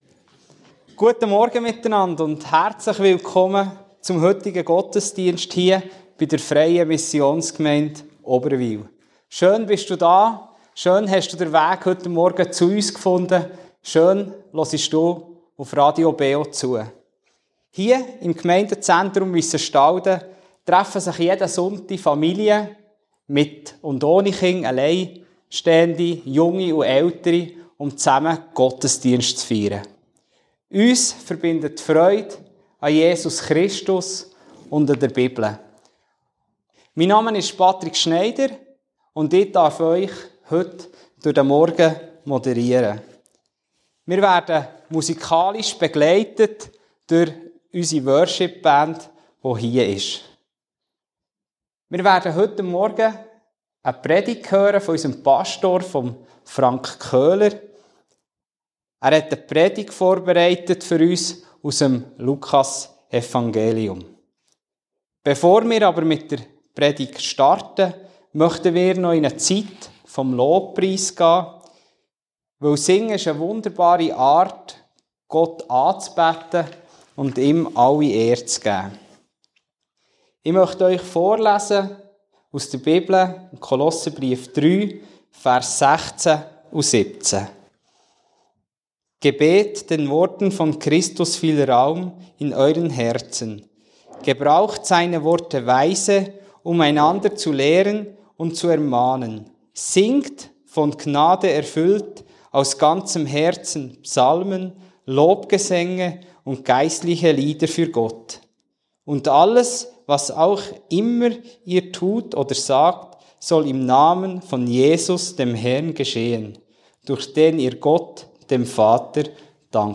BeO Gottesdienst